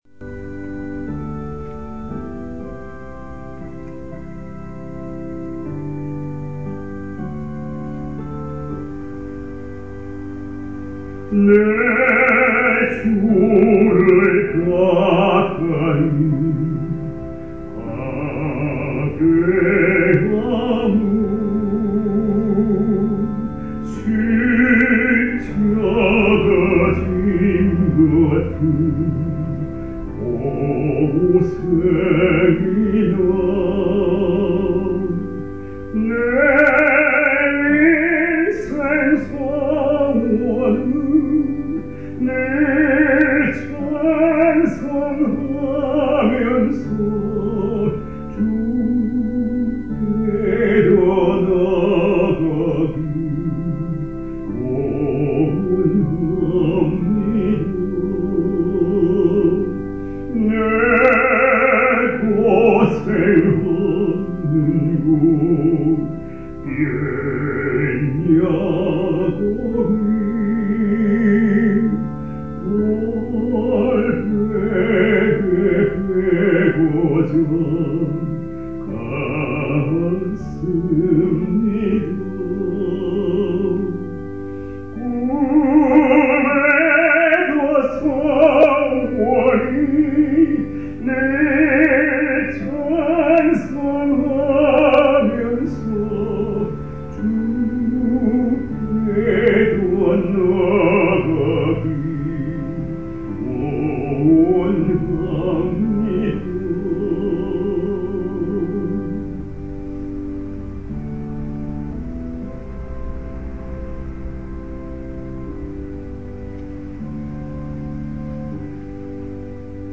불러 보고 싶었습니다.